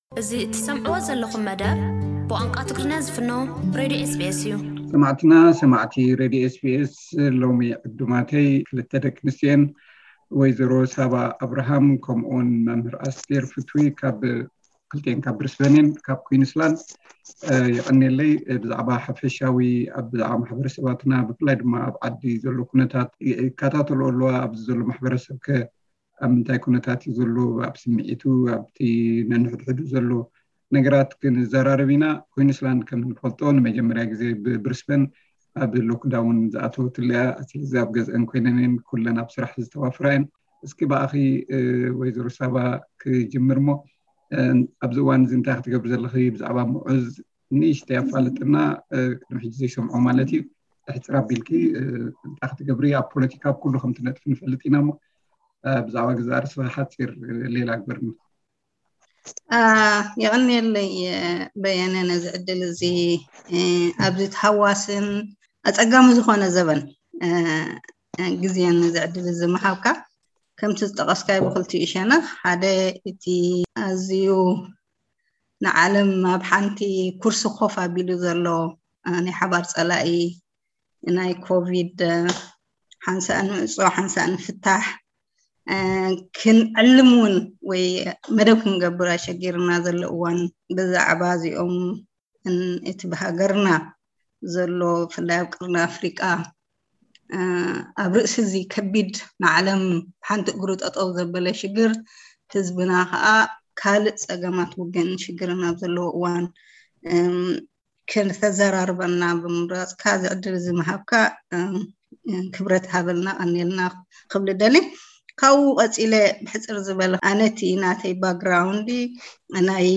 ዝርርብ ምስ መም